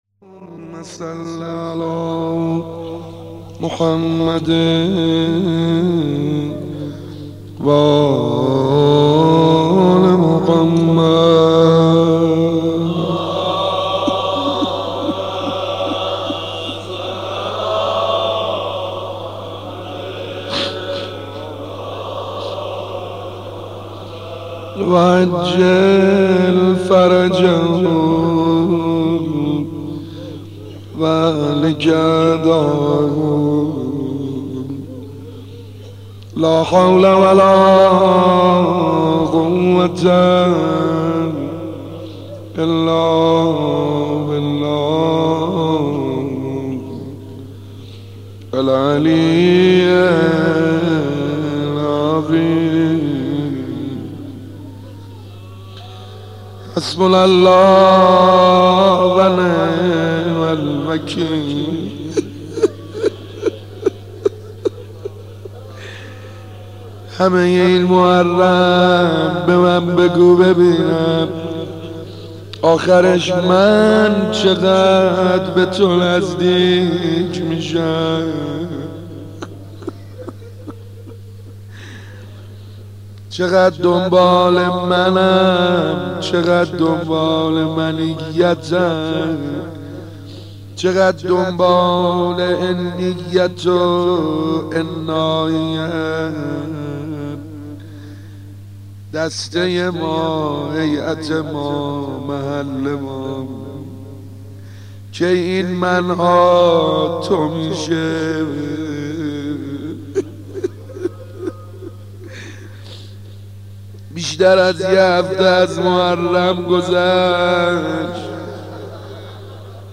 مداح
مناسبت : شب هشتم محرم
مداح : سعید حدادیان